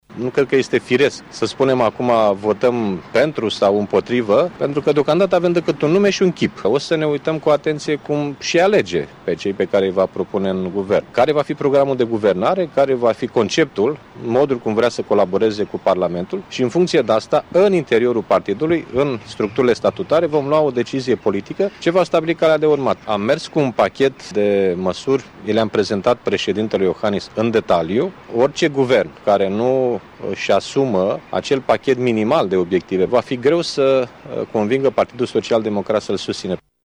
Partidul Social Democrat va aştepta până când premierul desemnat, Dacian Cioloş, îşi va prezenta în Parlament echipa şi programul de guvernare şi abia apoi va decide dacă va susţine noul guvern, a declarat preşedintele formaţiunii, Liviu Dragnea.